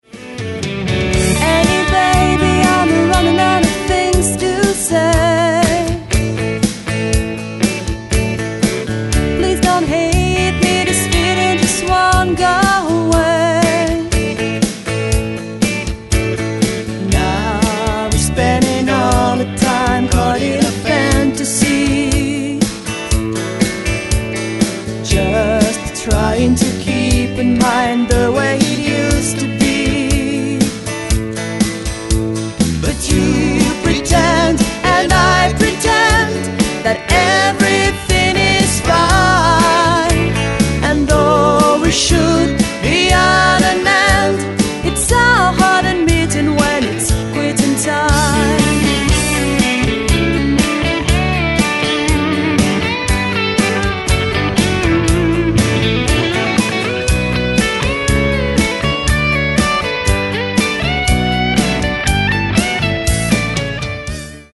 Chant, Harmonies
Pedal Steel Guitare
Guitare Acoustique & Electrique
Basse
Batterie